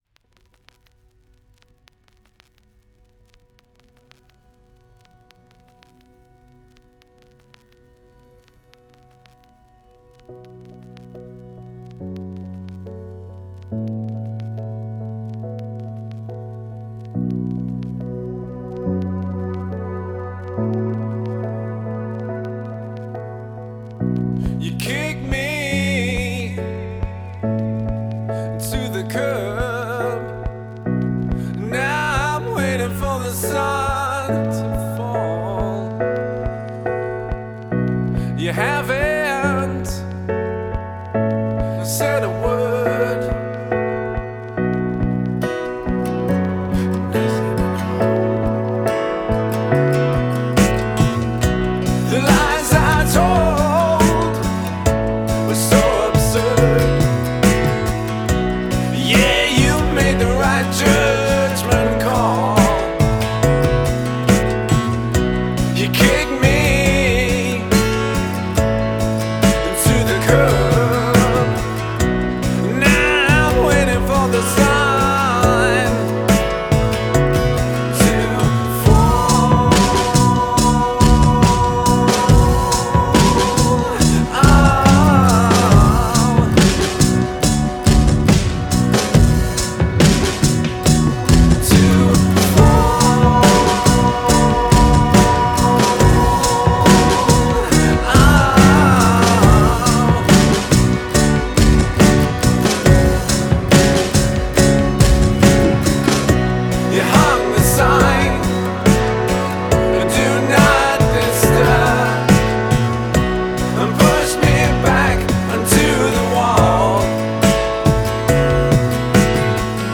Rondel
I love how it builds.
I loved that whispered fourth line, the prosody of it.
I really like the ambient plinky piano/synth thing.